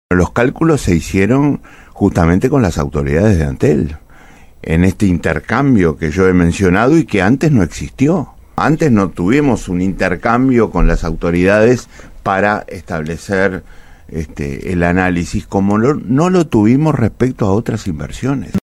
En entrevista en Radio Oriental, Astori afirmó que ahora se trabaja para corregir estos aspectos y que el principal objetivo es el "reordenamiento"; de las inversiones.